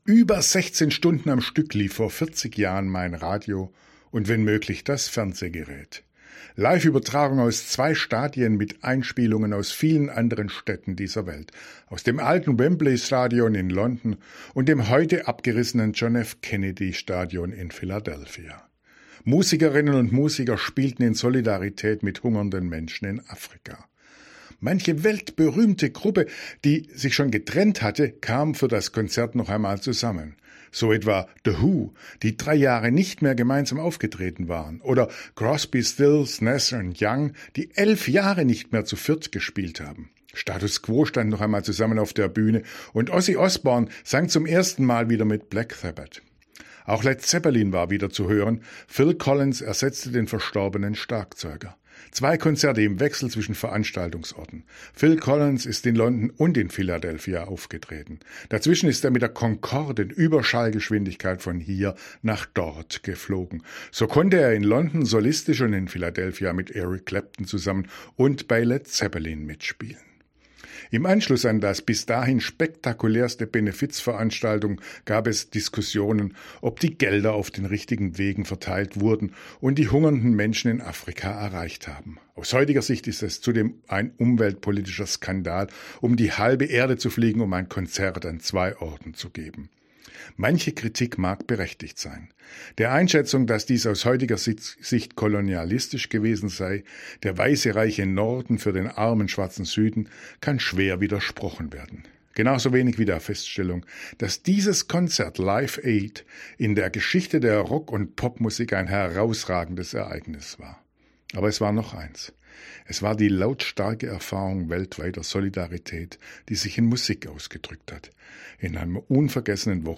Radioandacht vom 15. Juli – radio aktiv
radioandacht-vom-15-juli.mp3